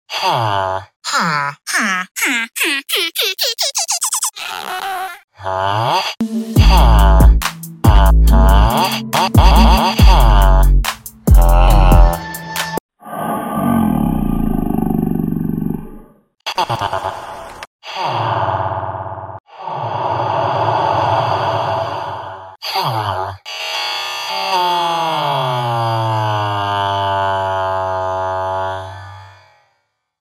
Minecraft villager huh
Mp3 Sound Effect